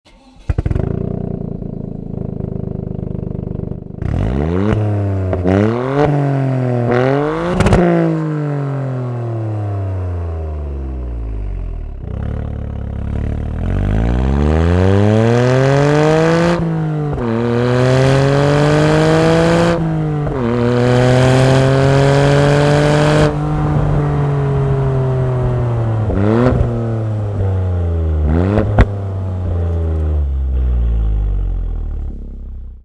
• Son de course sportif
Ligne complète Race Akrapovic :
Trois résonateurs – deux à l’arrière du collecteur et un dans le tuyau central – peaufinent davantage l’expérience sonore pour créer un son de course sportif , améliorant pleinement les caractéristiques du moteur avec un son profond et enthousiaste, pour donner aux occupants une sensation d’euphorie qui n’est jamais interrompue par un bruit excessif.
Les ingénieurs ont accordé une attention particulière à l’acoustique dans la plage de régime moyen et élevé, avec un son haute fréquence à des régimes plus élevés et plus de son au ralenti et à la montée en régime.
SLIP-ON-RACE-LINE-TOYOTA-GR-YARIS.mp3